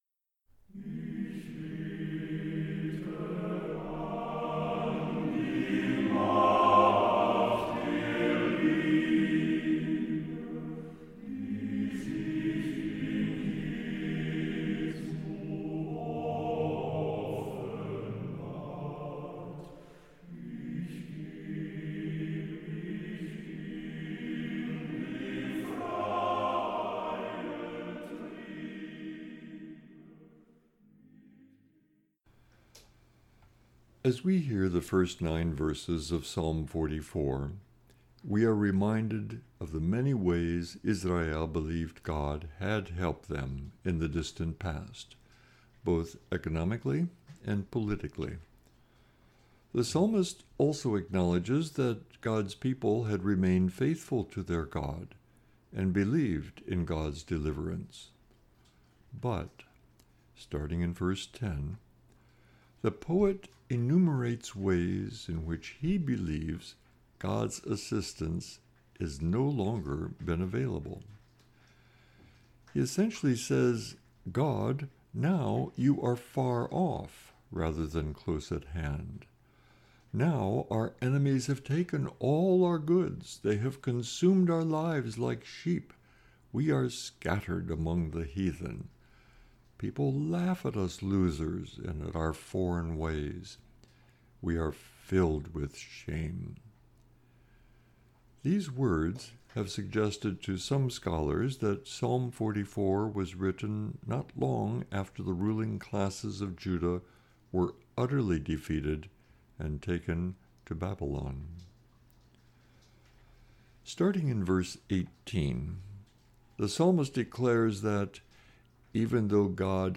Meditation - Point Grey Inter-Mennonite Fellowship